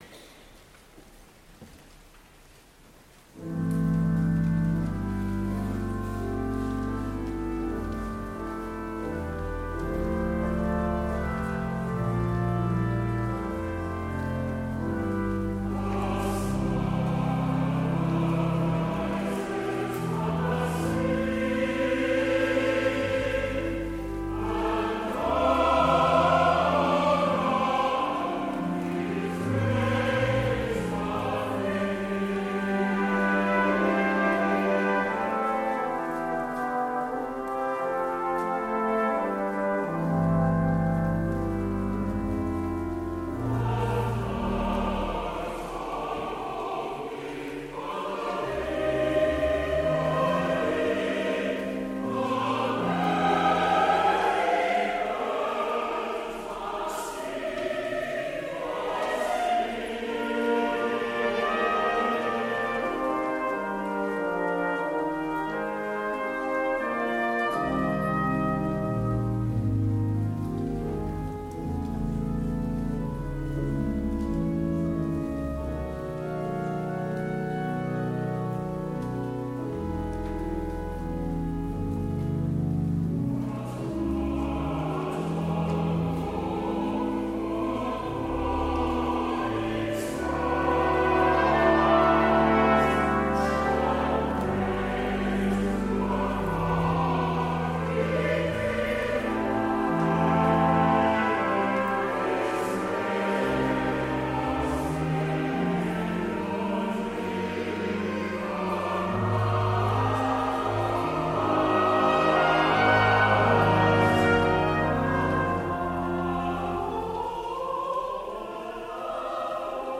Voicing: Brass Score